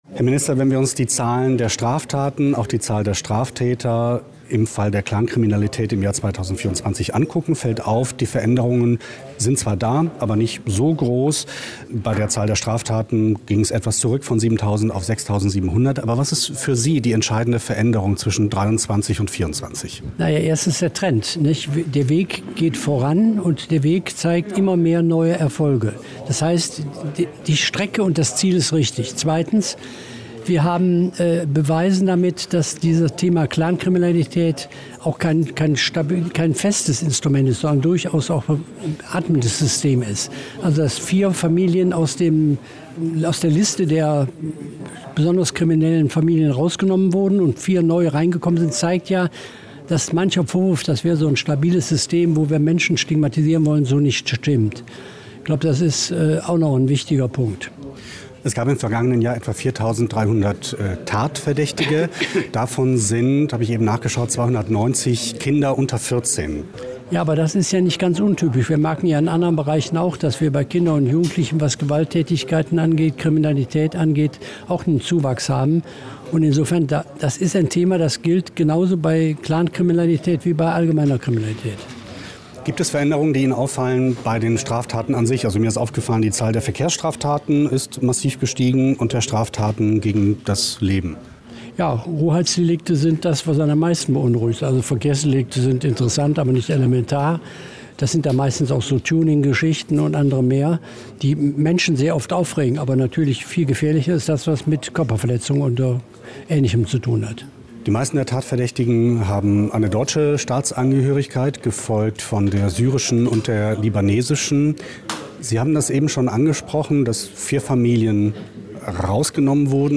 iv_innenminsiter_reul_zu_clankriminalitaet.mp3